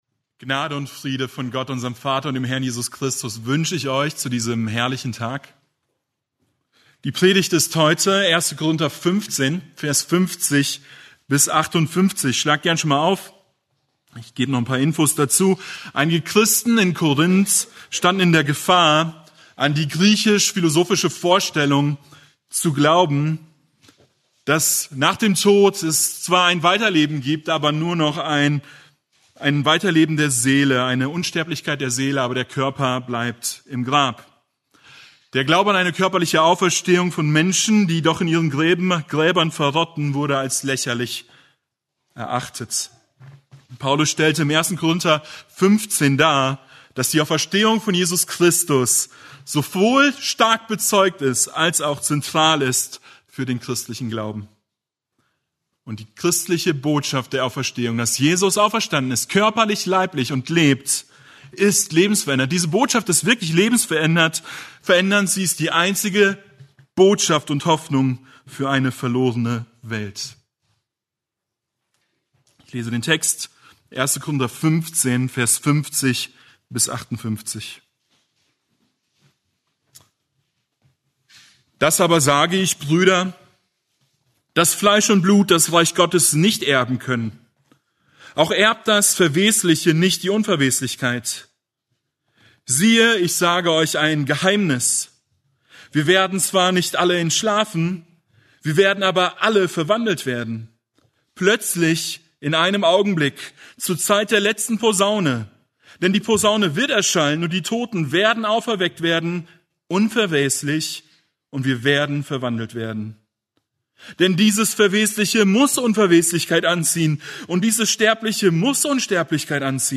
Eine predigt aus der serie "Einzelpredigten."